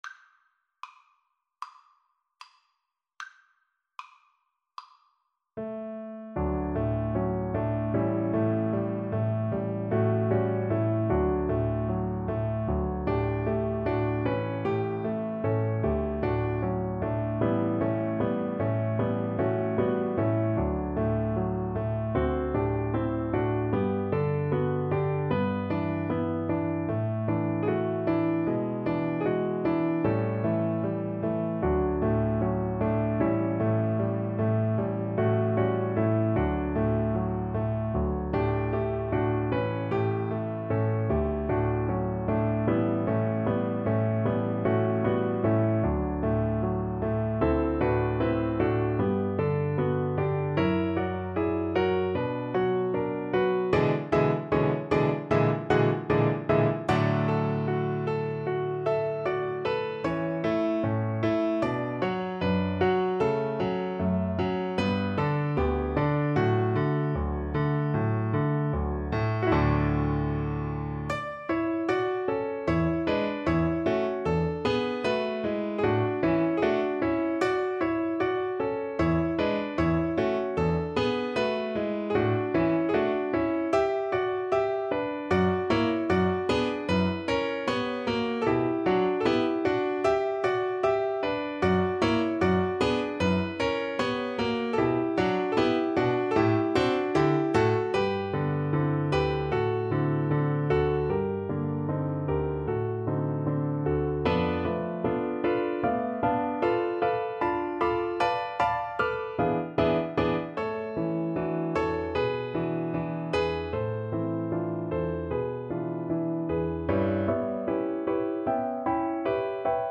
• Unlimited playalong tracks
Lento ma non troppo = c.76
Classical (View more Classical Viola Music)